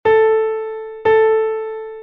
Notas con puntos encima